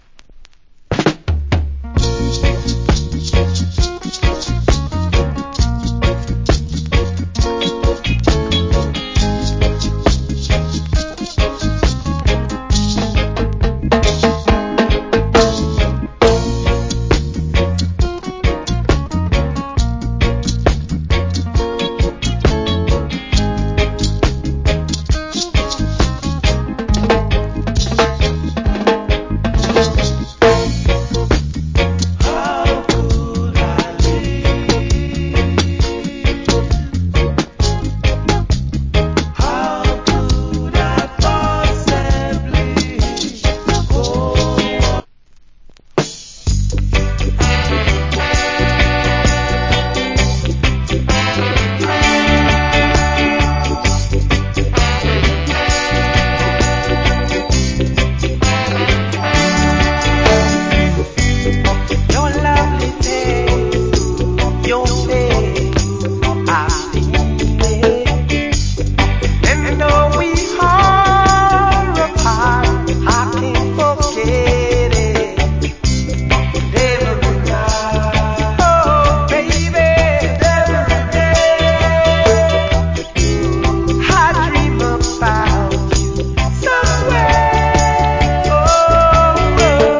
Cool Reggae Vocal + Dub.